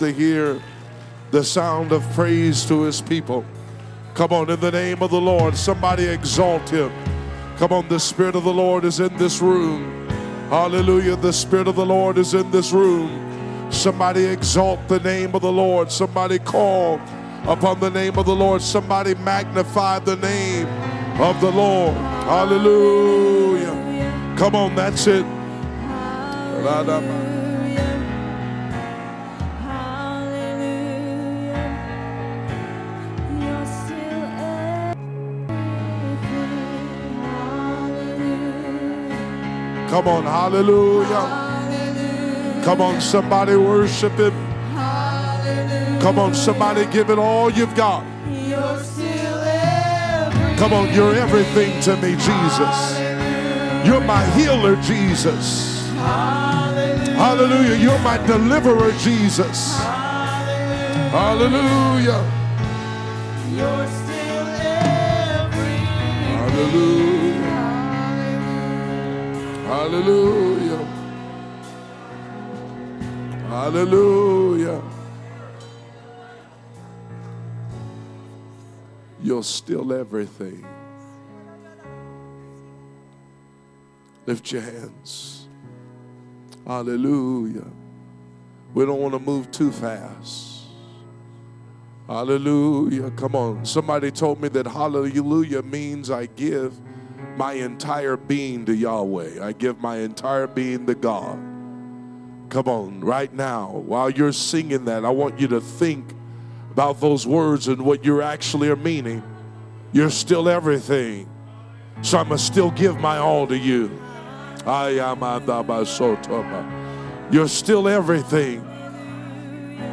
Tuesday Service